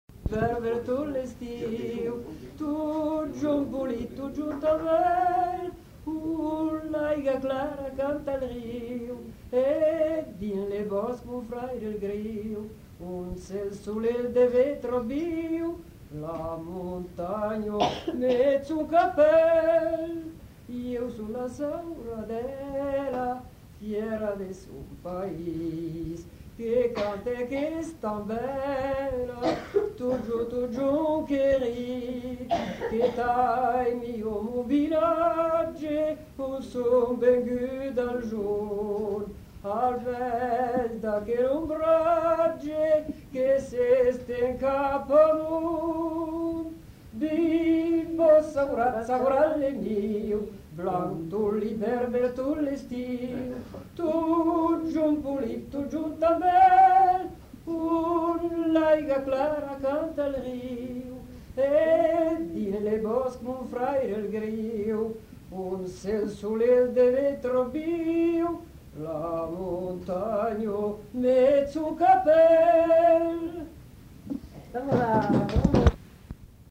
Lieu : Prat-Communal (lieu-dit)
Genre : chant
Effectif : 1
Type de voix : voix de femme
Production du son : chanté